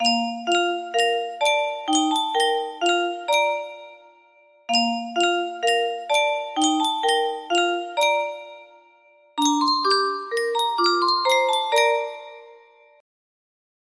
Yunsheng Music Box - I Know That My Redeemer Lives 1583 music box melody
Full range 60